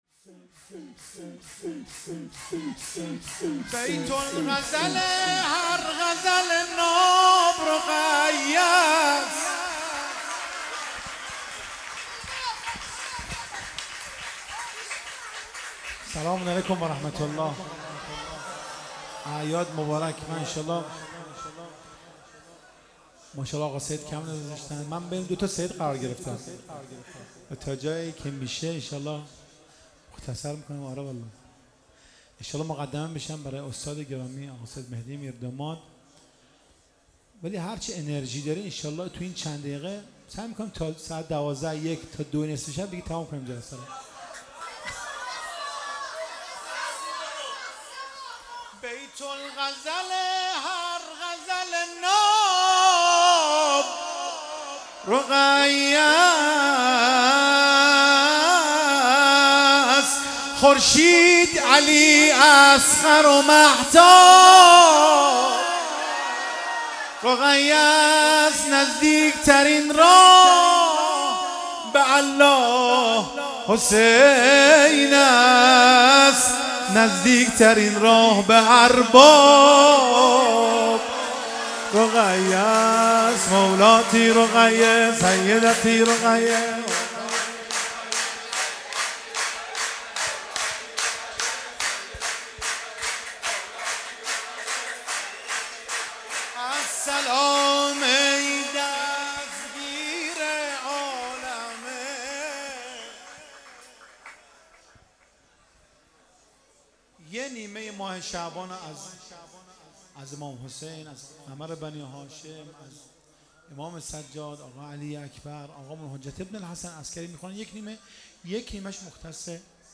ولادت حضرت رقیه (س)
مدح